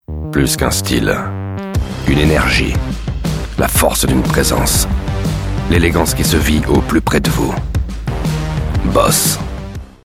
Tief, Warm, Corporate, Natürlich, Vielseitig
Kommerziell